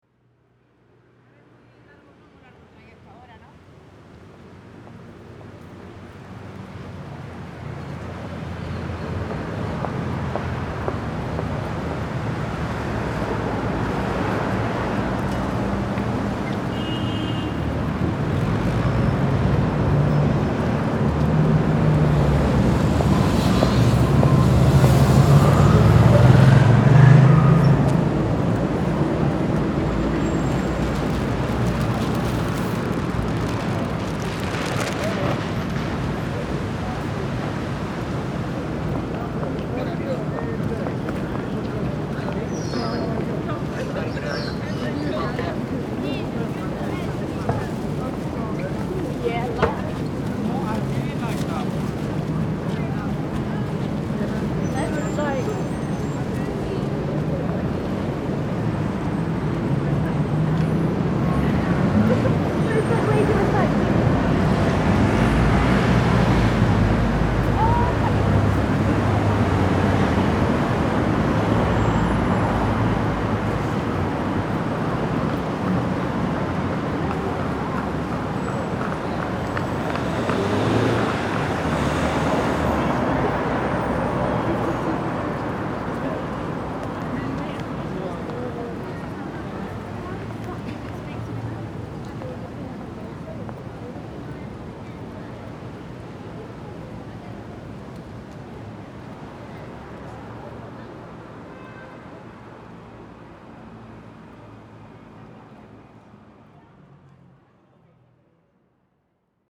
Notting Hill Gate
8 locations in london, 4 urban spaces, 4 unspoilt nature…
in contrast, areas such as notting hill gate and st. pauls are filled with urban drone and the bustle of pedestrians, although quieter than you’d imagine. oxford circus and liverpool street station however are exactly what you would expect – busy, noisy, smelly, managing to be both colourful and grey simultaneously.
8LondonLocations_02_NottingHillGate.mp3